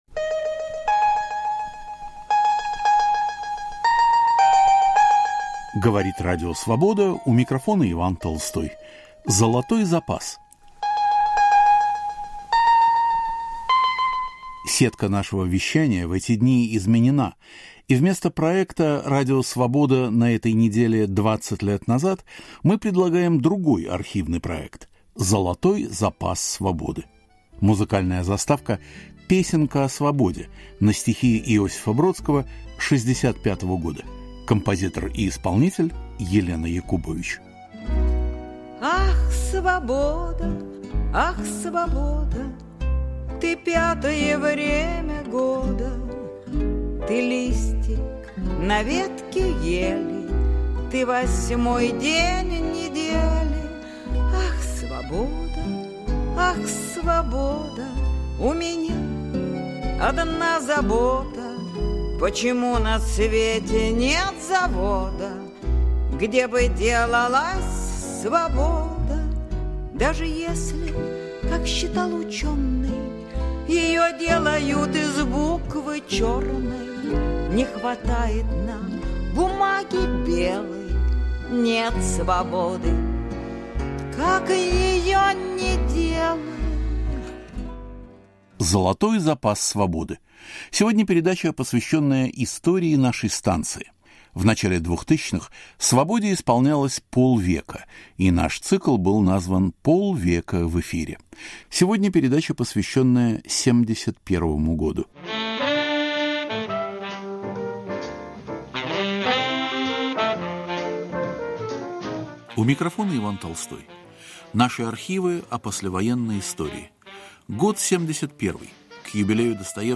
Цикл передач к 50-летию Радио Свобода. Из архива: разрешат ли еврейскую эмиграцию из СССР? 150-летие Достоевского.